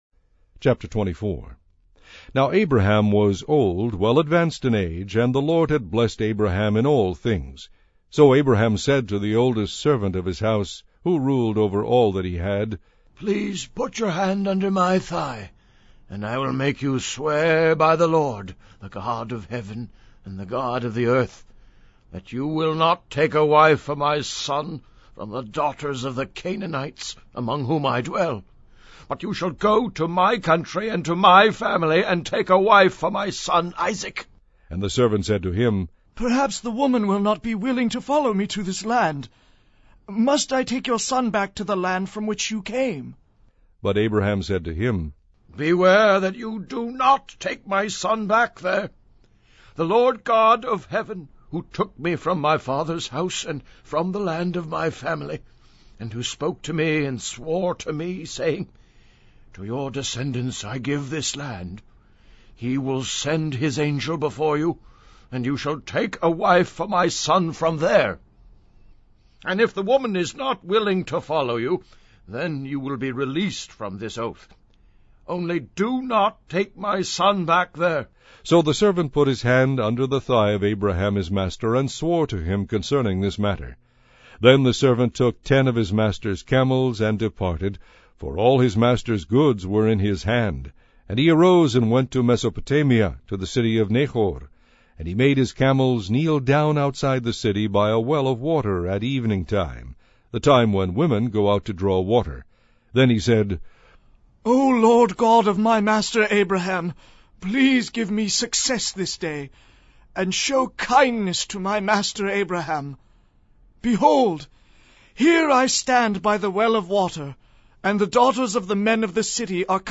The much-beloved NKJV comes to life in this fully dramatized audio product of the complete Bible.
and includes a full cast of seasoned performers–some Tony Award winners.
Dramatized Audio Bible NKJV Complete TN Sample.mp3